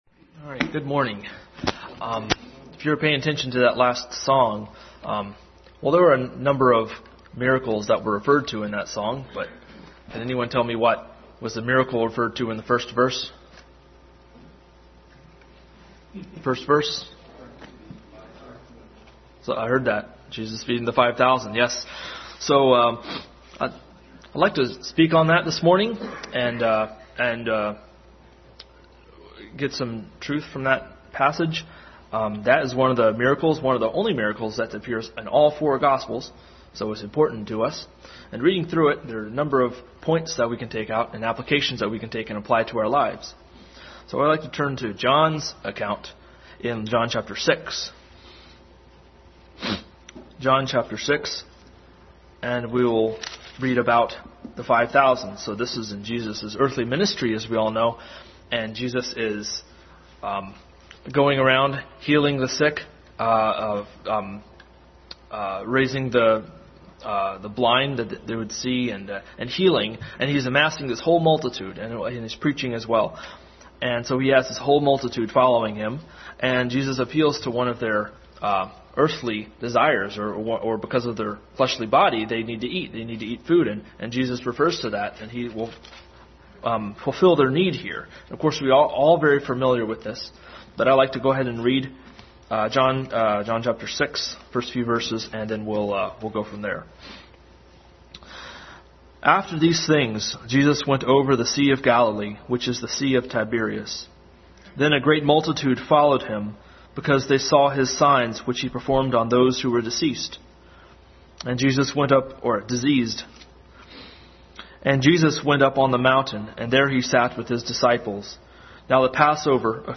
Feeding of the Five Thousand Passage: John 6:1-14, Matthew 18:1-5, Luke 9:16, 1 Kings 17:10-16 Service Type: Family Bible Hour Family Bible Hour Message.